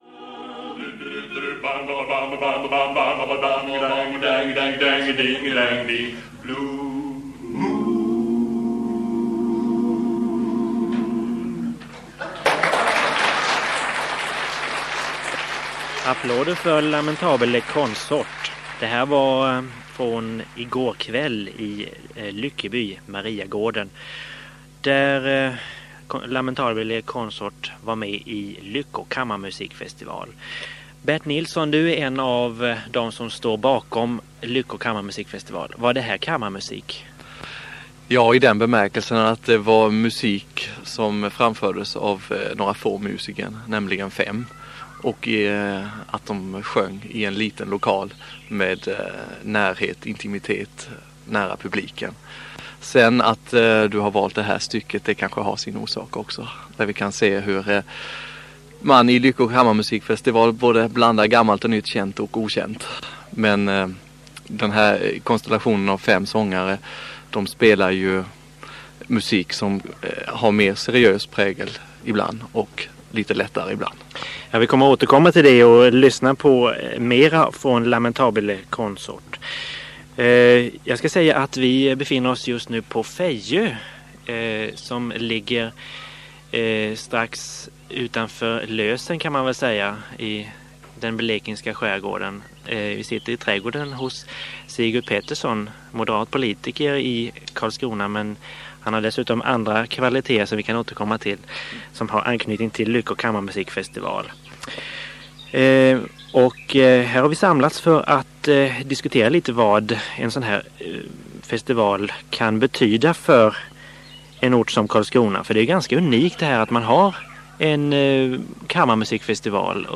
Samtal om Lyckå Kammarmusikfestival